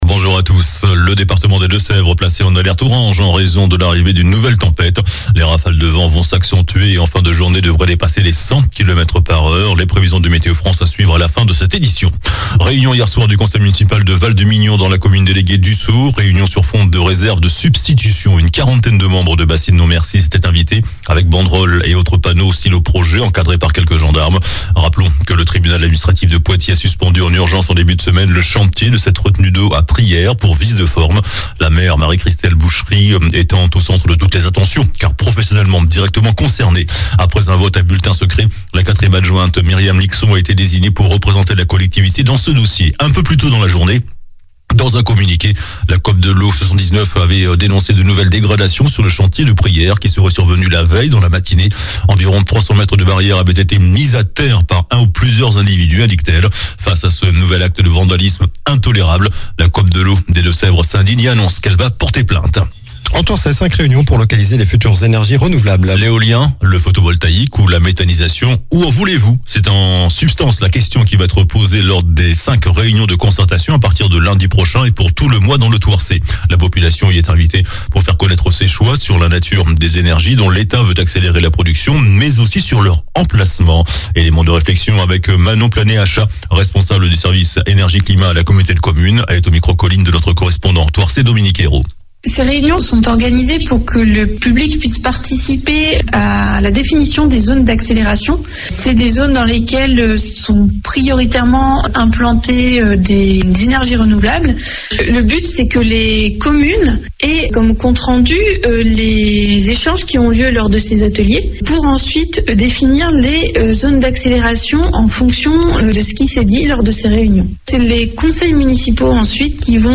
JOURNAL DU SAMEDI 04 NOVEMBRE